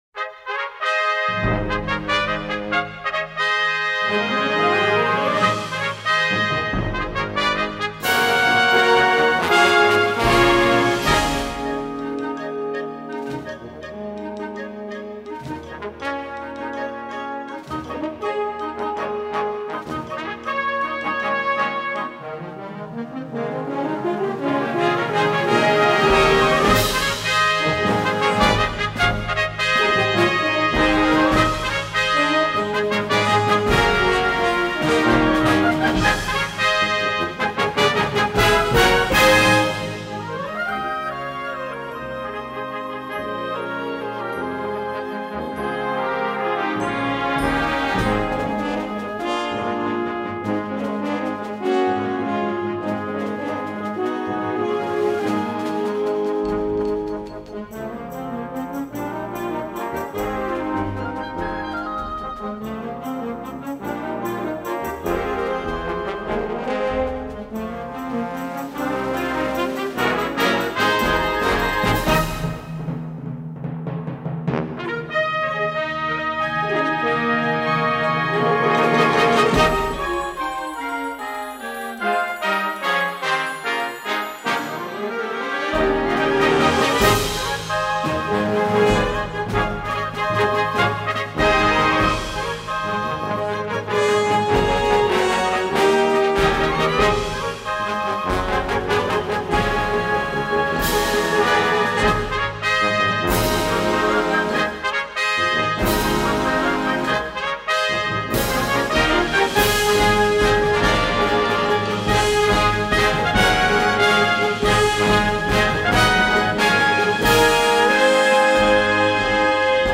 Besetzung: Blasorchester
Sometimes exciting, at other times quite reflective